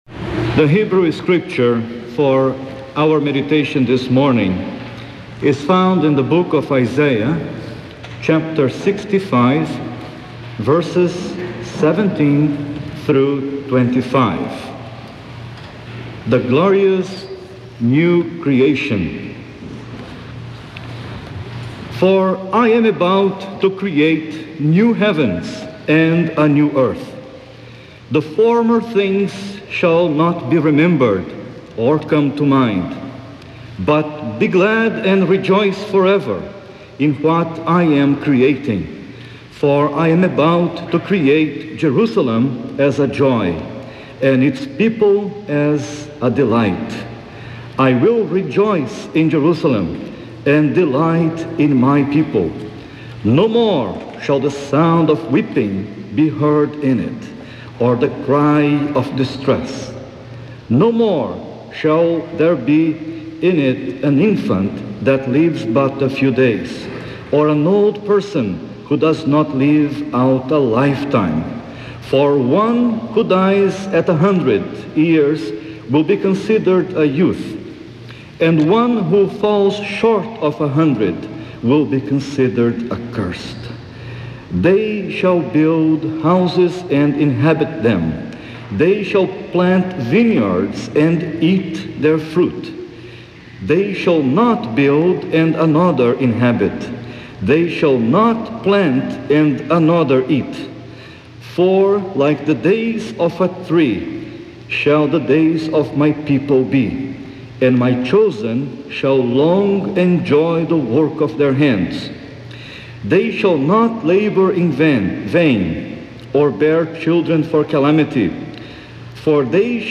Scriptures: Isiah 65: 17-25, Psalm 103, Acts 4:23, from Gospel of John. There is a break at 27:03 where the orginal cassette tape was turned over. The service was broadcast on WBUR, Boston.